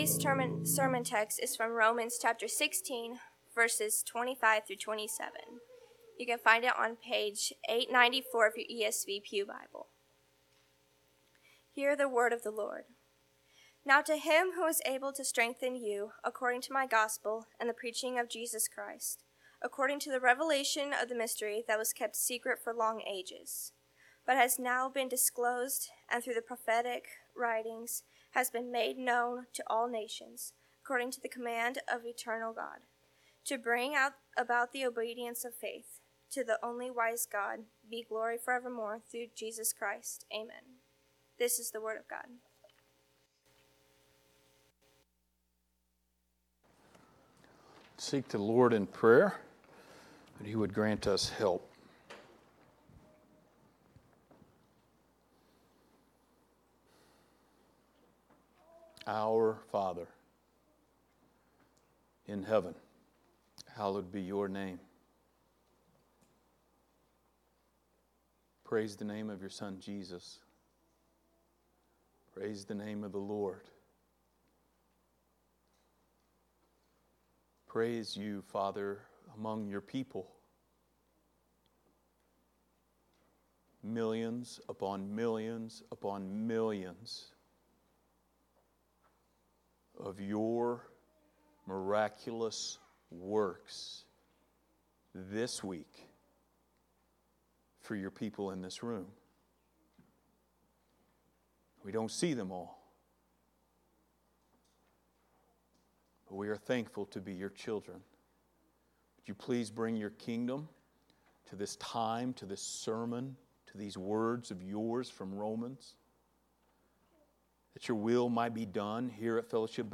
Book of Romans Passage: Romans 16:21-27 Service Type: Sunday Morning Related Topics